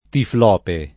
tiflope [ tifl 0 pe ]